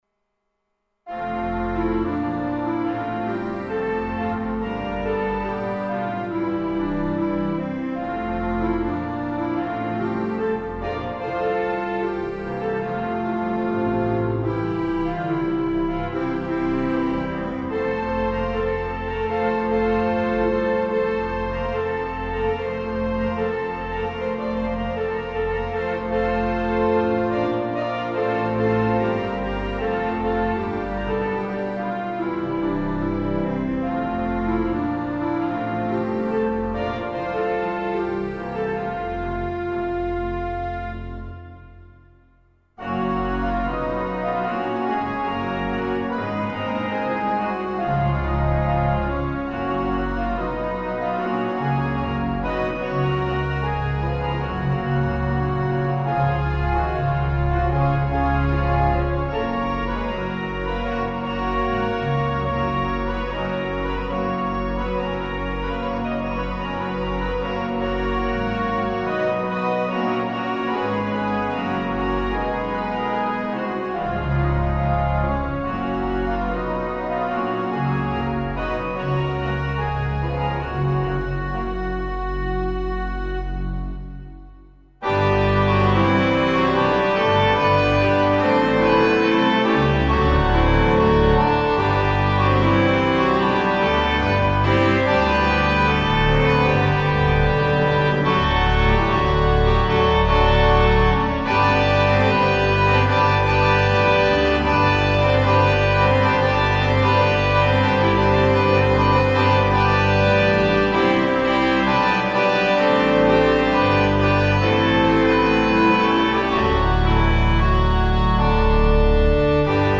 Here you can listen to screeching renditions of songs you will barely recognize!
Men of Harlech - This beautiful hymn (played too fast) was made famous by the move Zulu (which was Micheal Cain's first movie) about the men, about 150, holding out at Rourk's Drift against thousands of Zulus in Africa after the Zulu army wiped out a British Column in the morning (movie Zulu Dawn).
Again my playing on the 3-31, and I also used the transposing capabilities of the Hauptwerk software.  32' stops are employed so check your sound system with it.